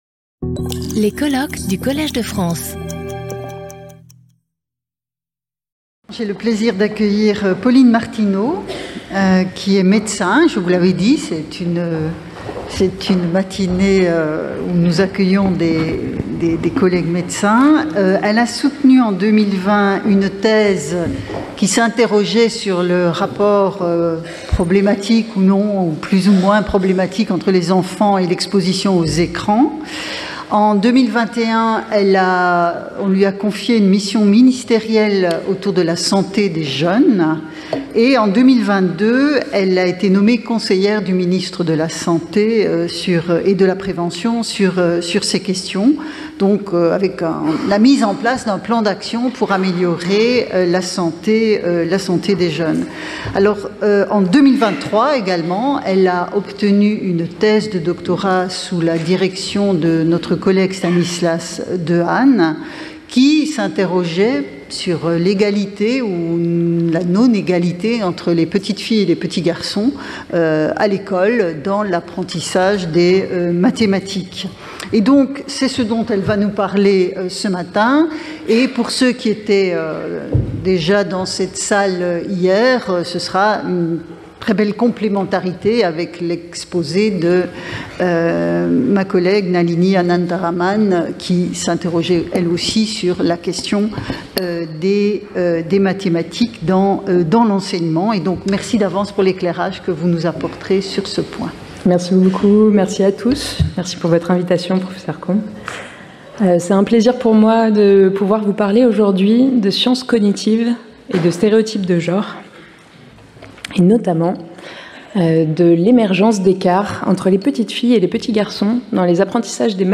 Chaque communication de 30 minutes est suivie de 10 minutes de discussion.